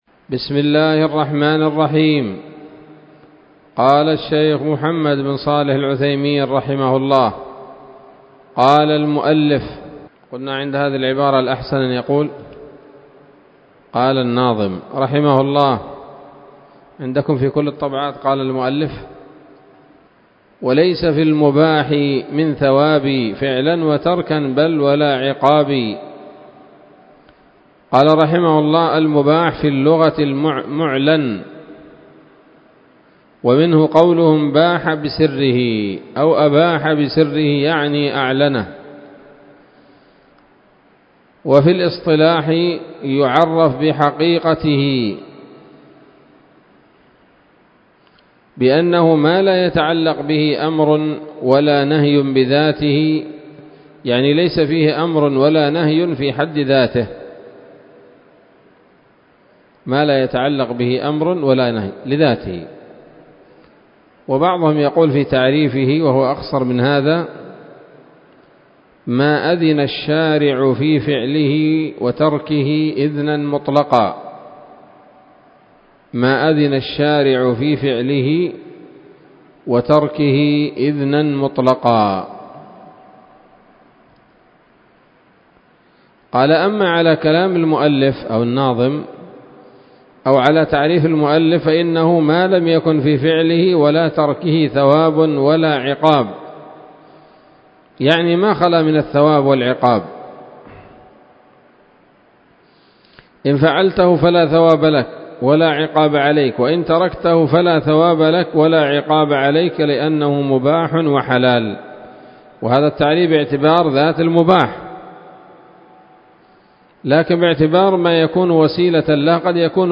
الدرس الثاني عشر من شرح نظم الورقات للعلامة العثيمين رحمه الله تعالى